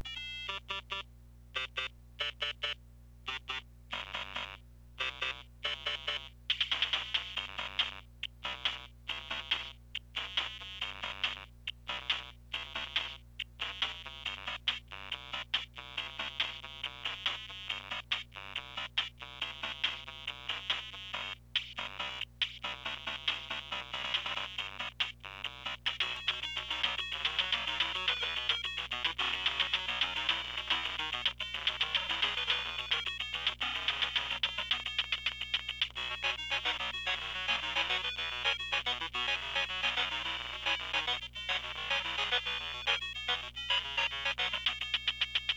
All Atari Lynx II's used for these speaker tests, had there volume control wheel turned to Maximum sound volume out and the recording microphone was placed the same distance from each Lynx II built in speaker.  The Atari Game cartridge used to run the sound tests is the Lynx California Games start up screen and then the second California 4 game selection screen.
Used Atari Lynx II Spearker going bad.MP3
Going bad Lynx II speaker 2.wav